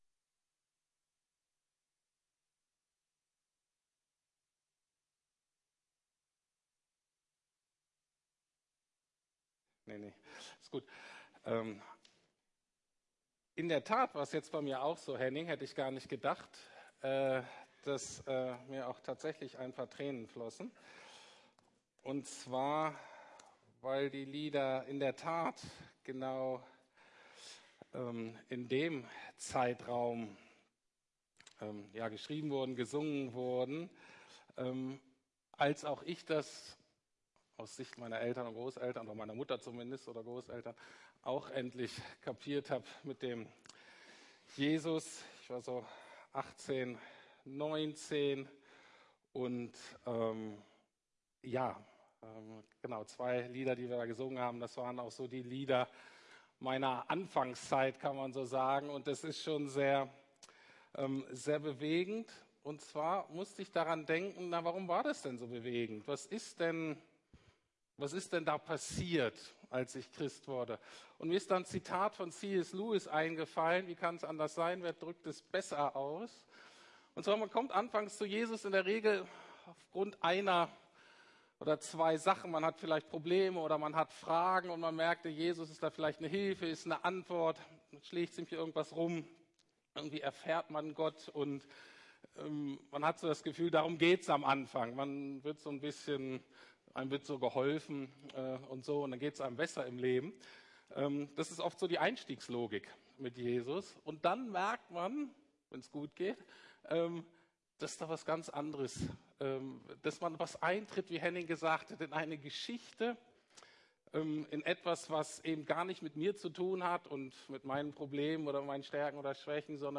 Der gesandte Gott und die Gesandten Gottes ~ Predigten der LUKAS GEMEINDE Podcast